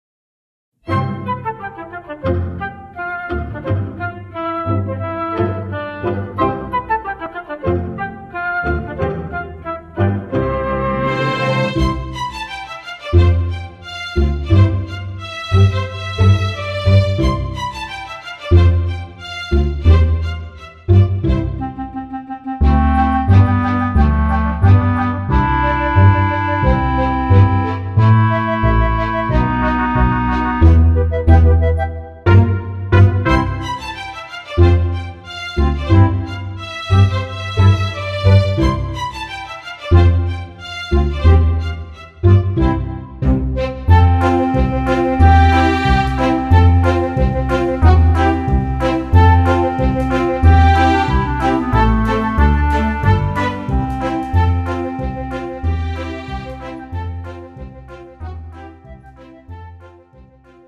(뮤지컬) MR 반주입니다.